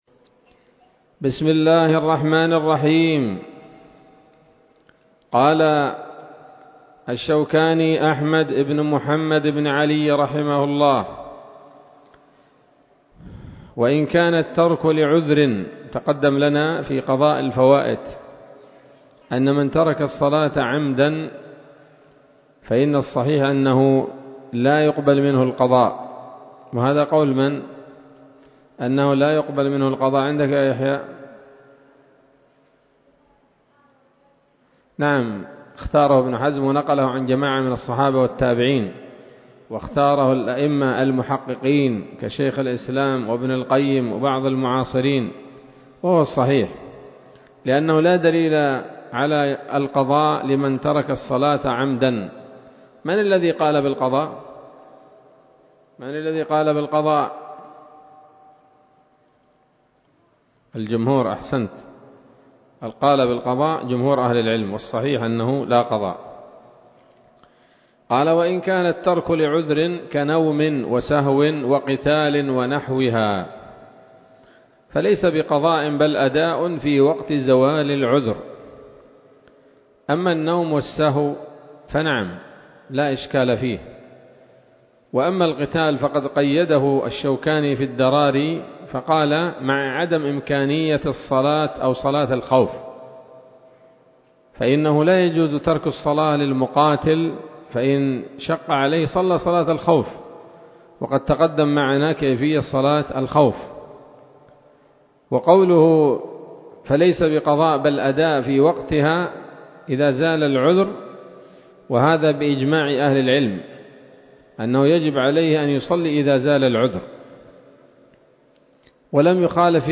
الدرس الثامن والثلاثون من كتاب الصلاة من السموط الذهبية الحاوية للدرر البهية